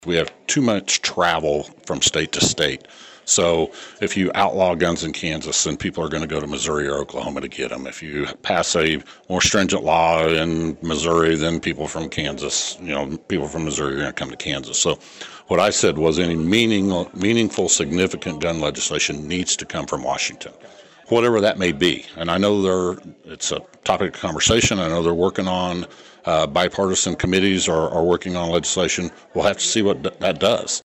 The Emporia Area Chamber of Commerce Government Matters Committee and League of Women Voters’ final legislative dialogue of the year drew a significant crowd to the Trusler Business Center Tuesday night.